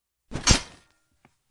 中世纪的战斗 " 斧头冲击3
用Tascam记录金属光栅的声音。
增加了拳头击打胸部和其他低冲击频率的额外声音，以产生更大的影响
Tag: 血腥 战斗 战斗 金属 中世纪 打击 冲击